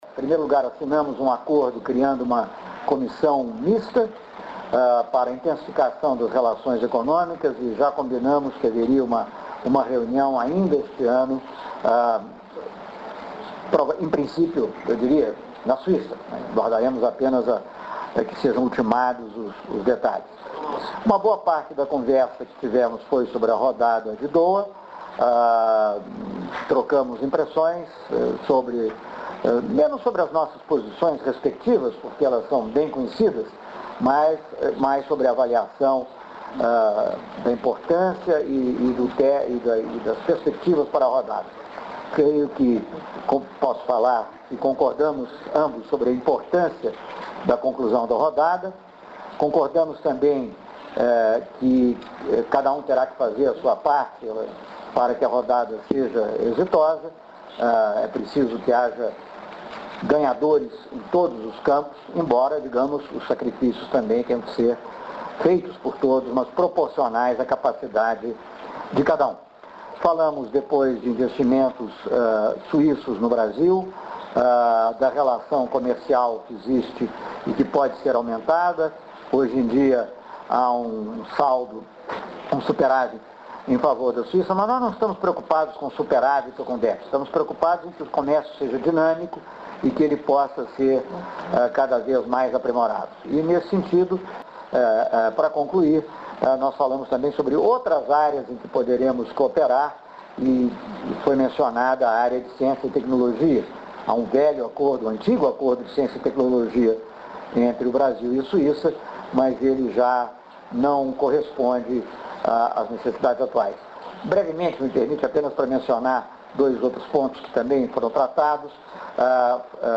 O ministro das Relações Exteriores, Celso Amorim, explica o acordo para a Criação de uma Comissão Mista para dinamizar as relações econômicas e comerciais.